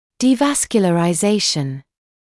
[ˌdiːˌvæskjələraɪ’zeɪʃn][ˌдиːˌвэскйэлэрай’зэйшн]деваскуляризация; прекращение или нарушение кровоснабжения